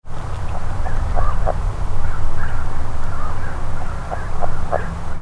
The vocalizations of Rana aurora can be described as a very quiet weak series of 5 - 7 notes, sounding like uh-uh-uh-uh-uh, lasting 1 - 3 seconds.
All sounds were recorded in the air (not underwater) at a close distance with a shotgun microphone.
Background sounds include: various birds calling and moving around in dry reeds, including ravens and song sparrows; flowing water; distant traffic; and distant farm equipment.
Sound  This is a 5 second recording of the advertisement calls of a single frog.